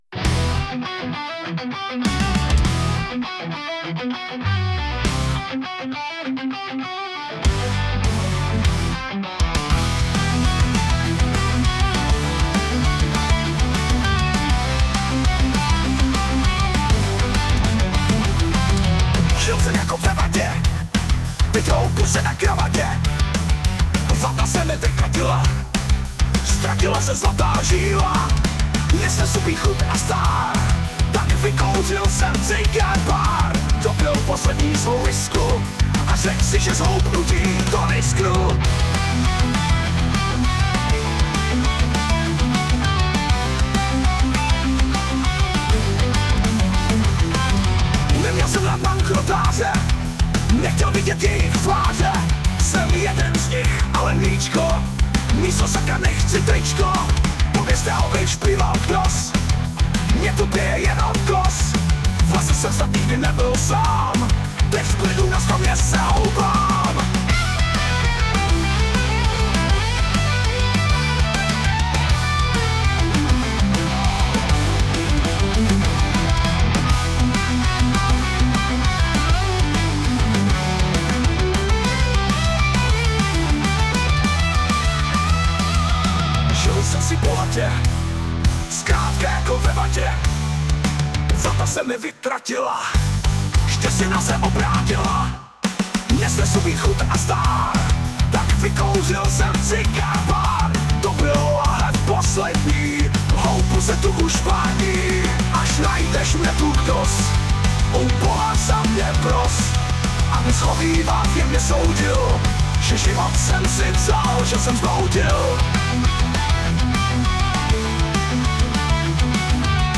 hudba, zpěv: AI